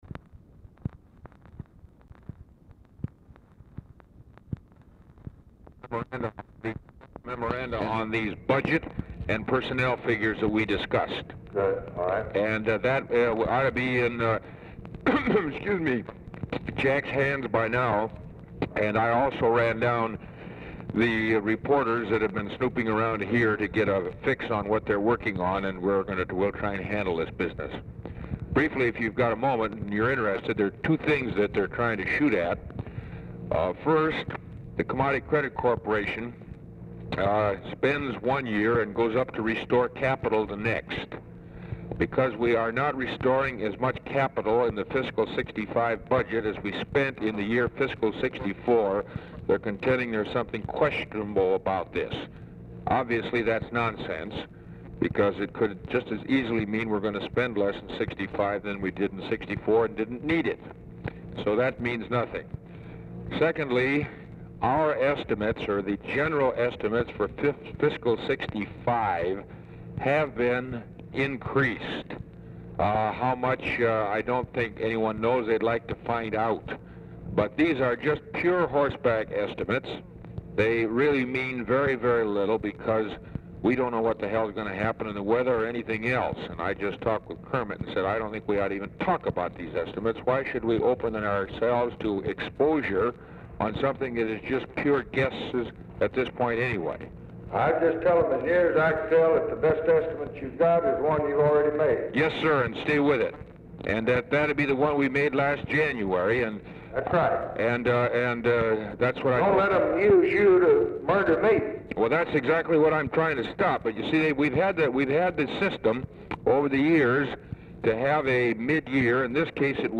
Telephone conversation # 3712, sound recording, LBJ and ORVILLE FREEMAN, 6/12/1964, 5:26PM | Discover LBJ
Format Dictation belt
Location Of Speaker 1 Oval Office or unknown location